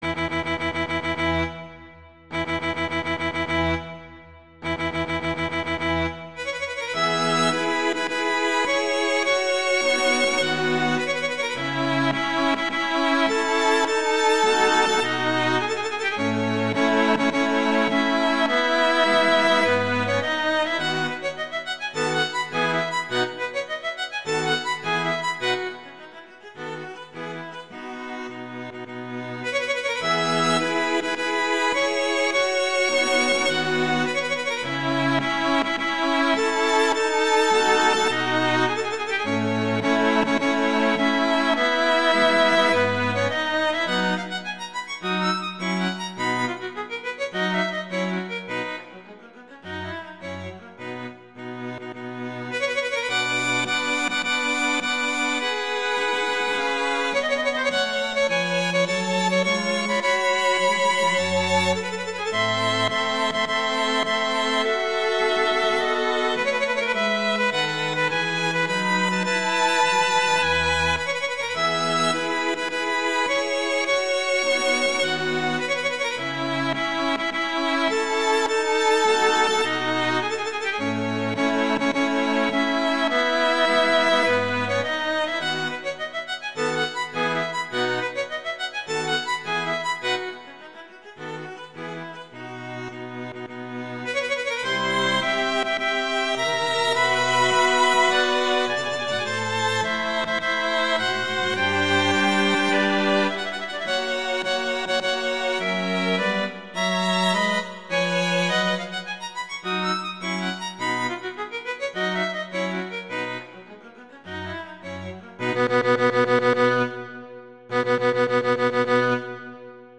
Arrangement by A for string quartet.